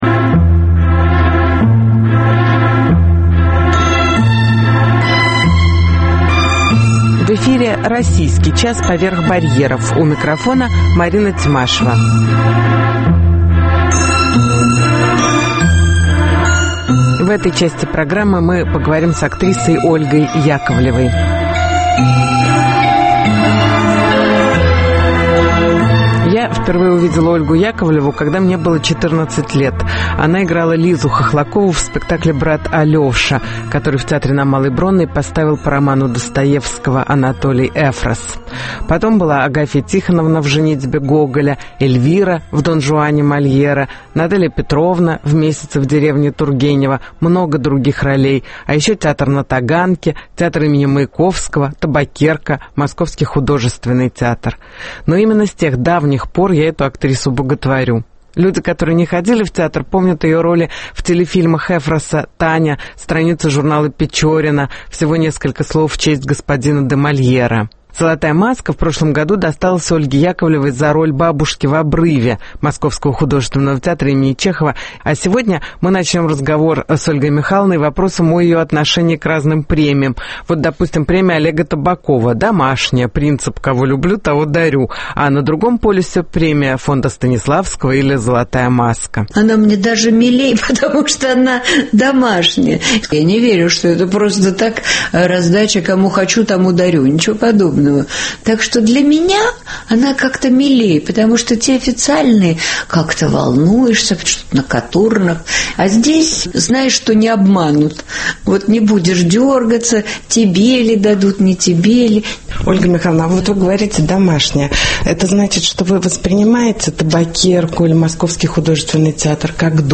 Интервью с актрисой Ольгой Яковлевой